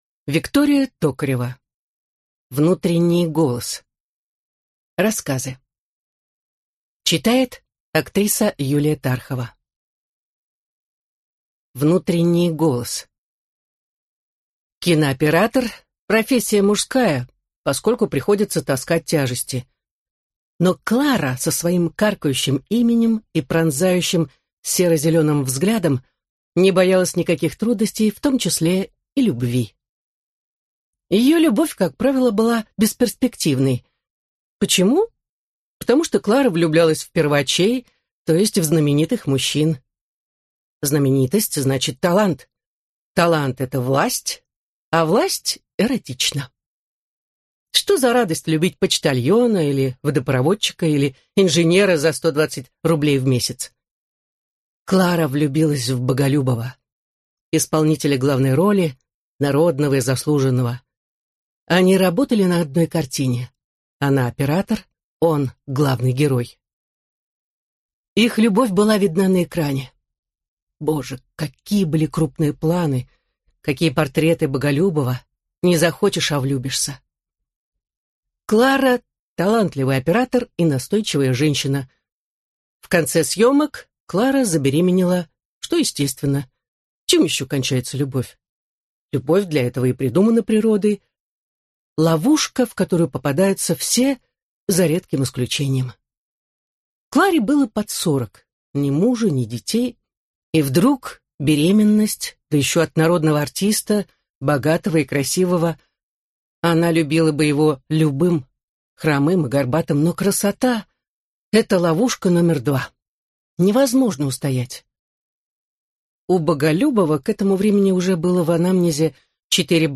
Аудиокнига Внутренний голос | Библиотека аудиокниг